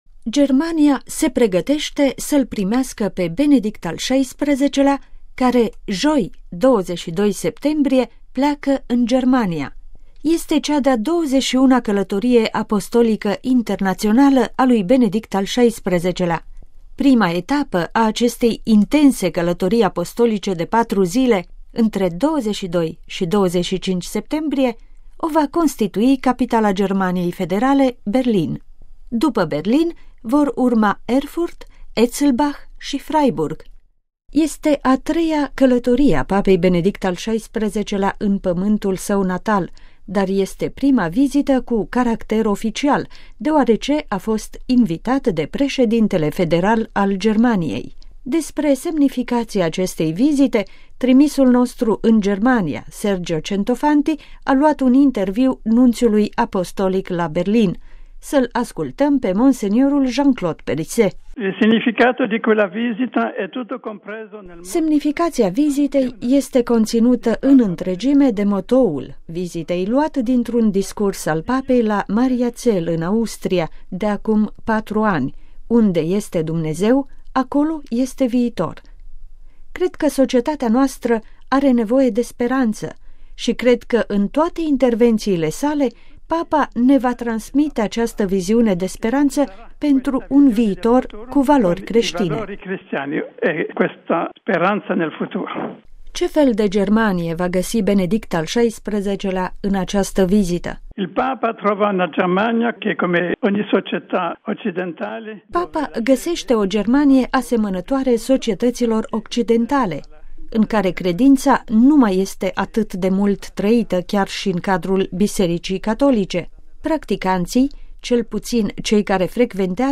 Să-l ascultăm pe mons. Jean-Claude Périsset: